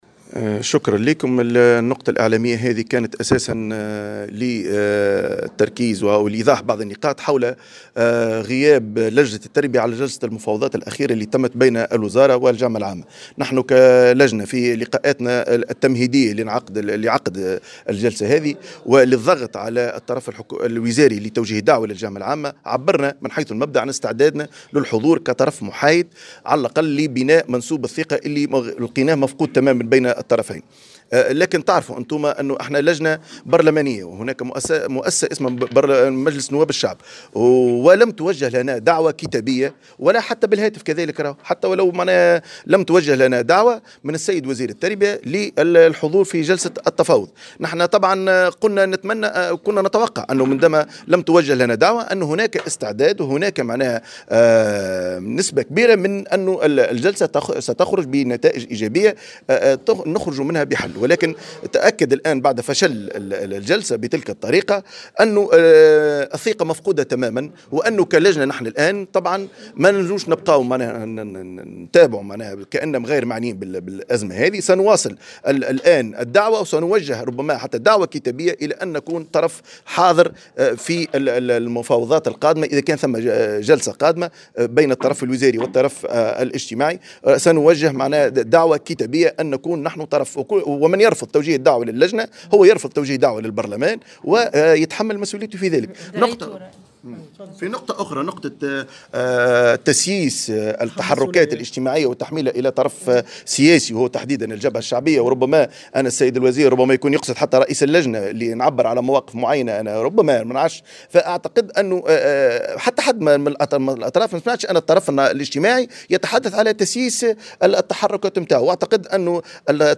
وقال رئيس لجنة التربية هيكل بلقاسم في تصريح لمراسل "الجوهرة اف ام" إن اللجنة ستتقدم بطلب رسمي للوزارة لحضور جلسة التفاوض المقبلة.